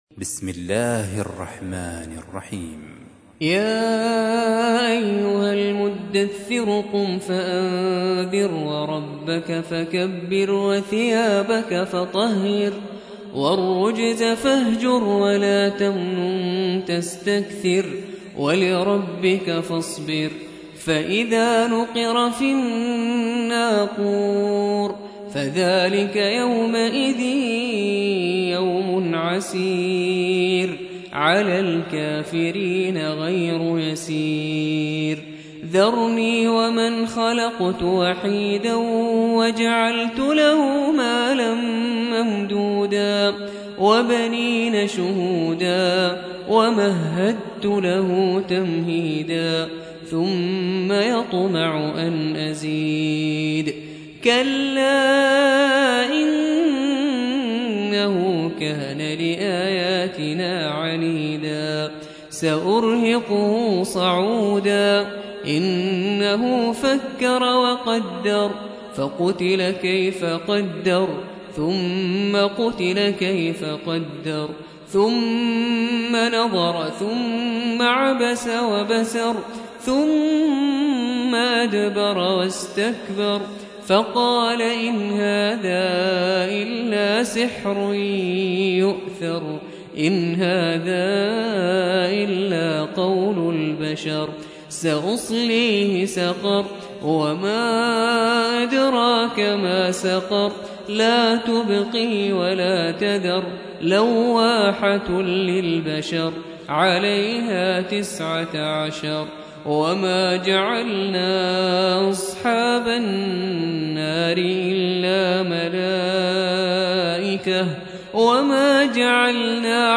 74. سورة المدثر / القارئ